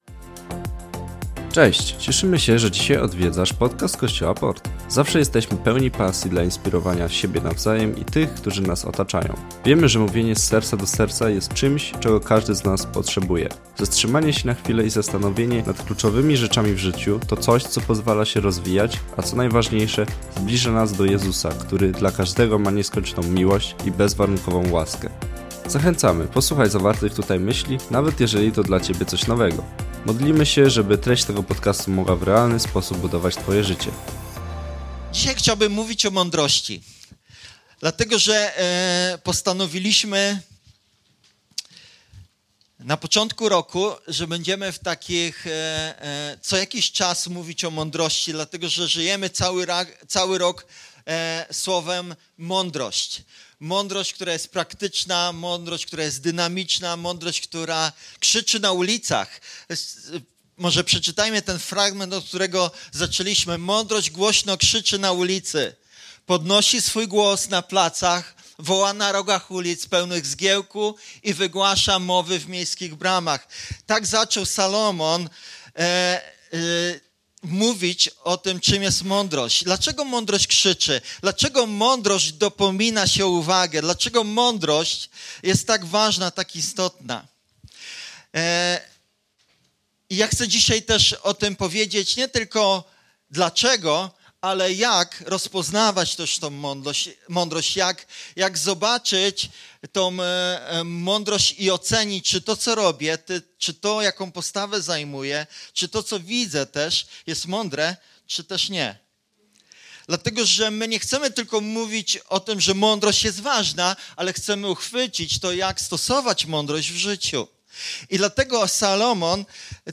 Złap chwilę i posłuchaj naszego kazania o tym, jak można rozpoznać mądrość.